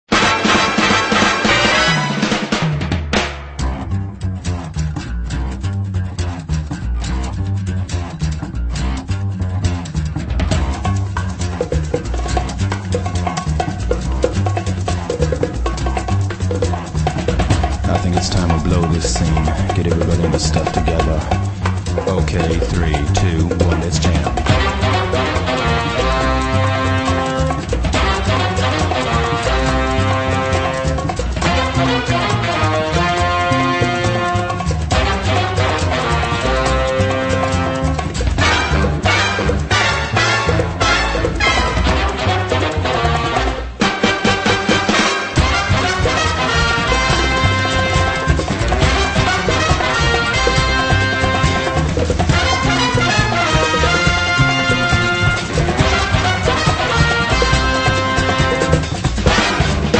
• Jazz Ringtones